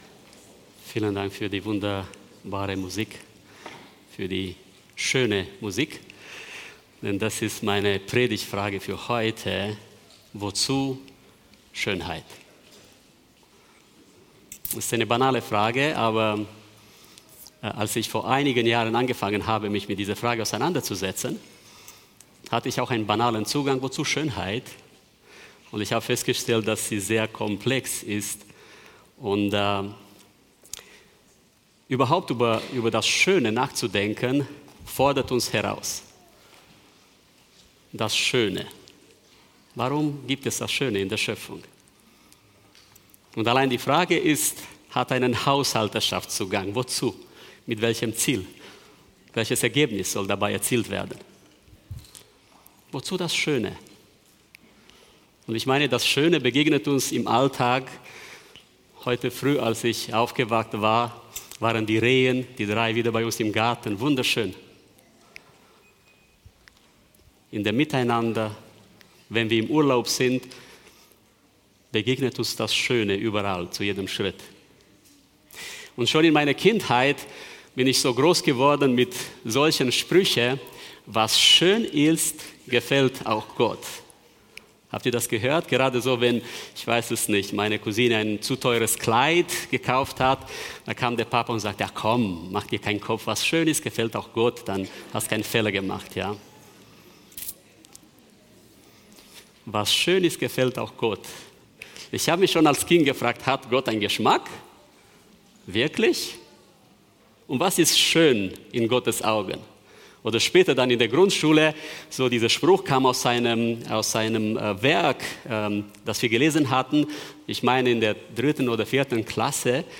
Mitschnitt vom 31.01.2026 zum Thema „Verschwendung"